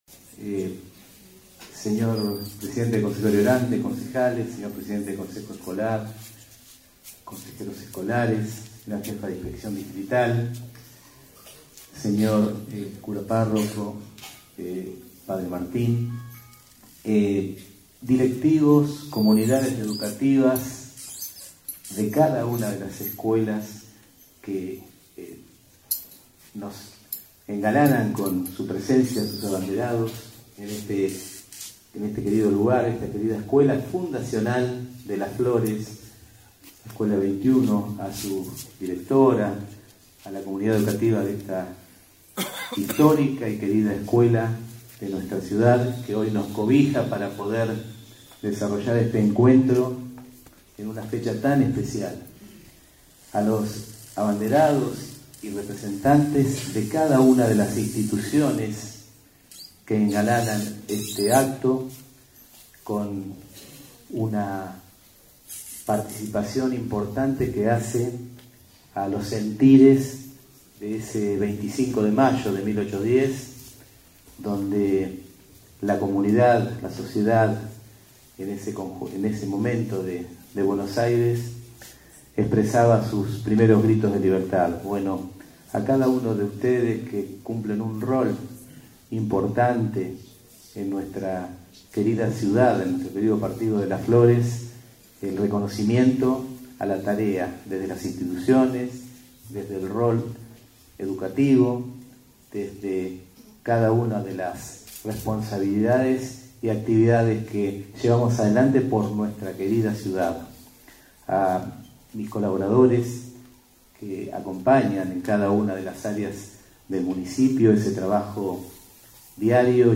Se llevó a cabo en la mañana de hoy jueves el acto centralizado por el 213° Aniversario de la Revolución de Mayo.
Acto-25-Patrio.mp3